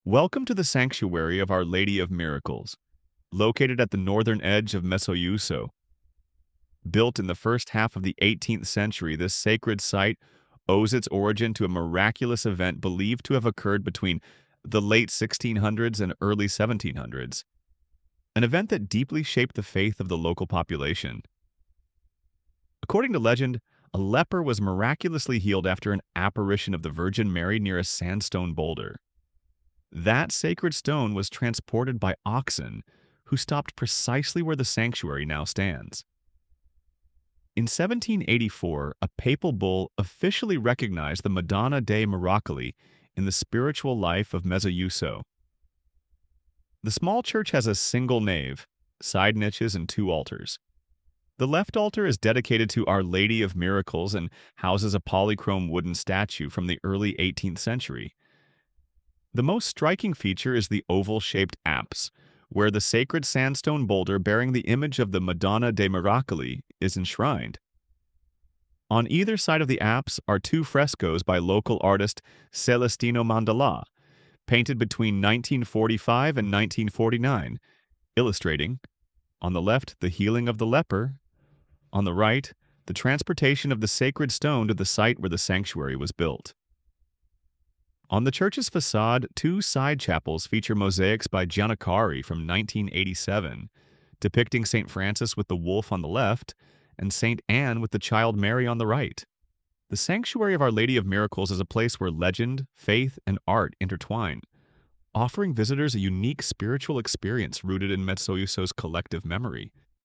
Audio Guida